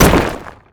rock_impact_small_hit_01.wav